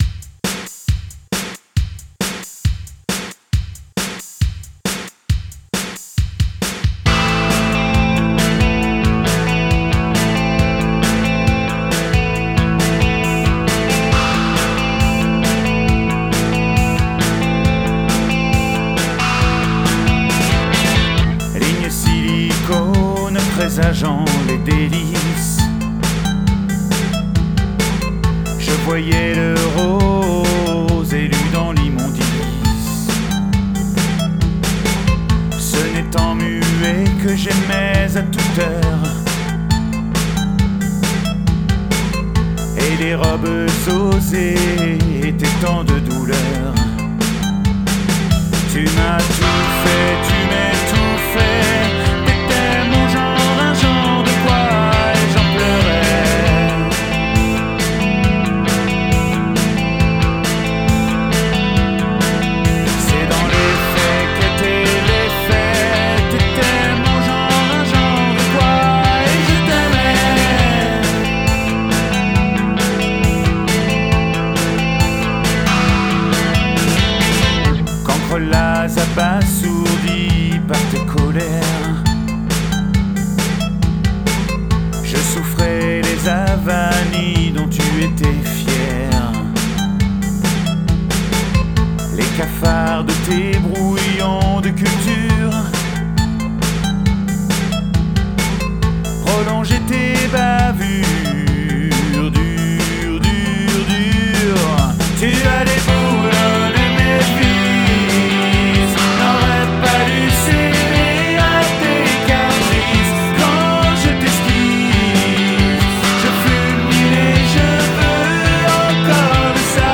DEMO
Homestudio RECORDING